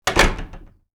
DoorClose2.wav